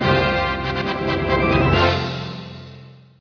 win.wav